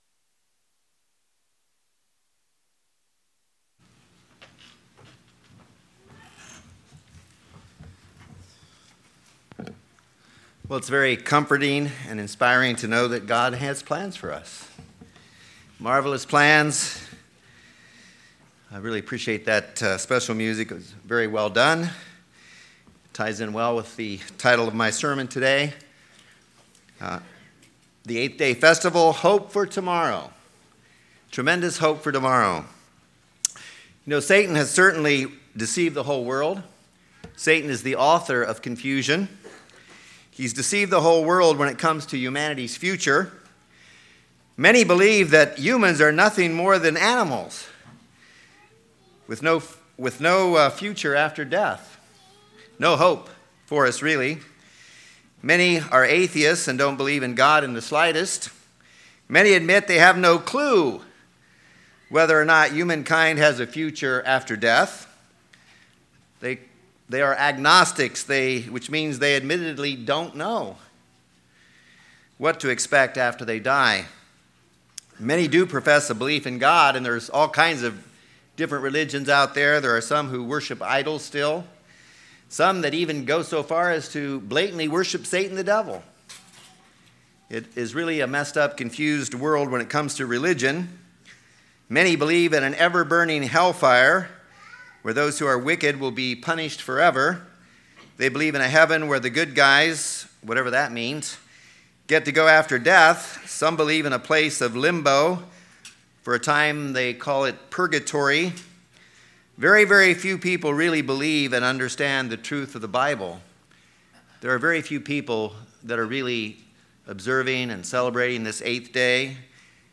This sermon was given at the Galveston, Texas 2023 Feast site.